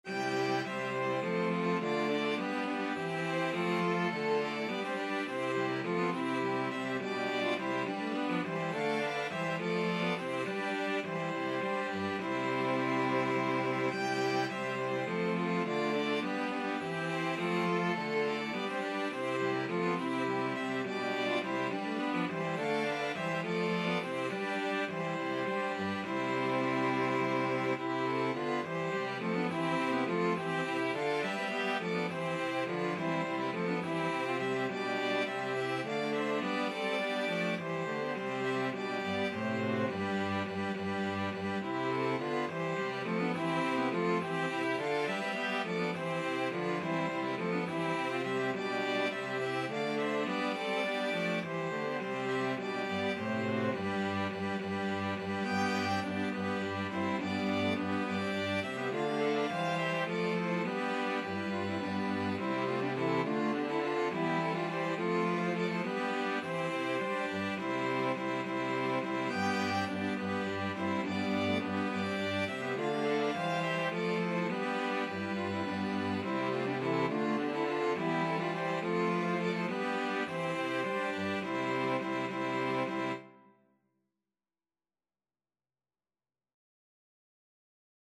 Free Sheet music for String Ensemble
6/8 (View more 6/8 Music)
C major (Sounding Pitch) (View more C major Music for String Ensemble )
String Ensemble  (View more Advanced String Ensemble Music)
Classical (View more Classical String Ensemble Music)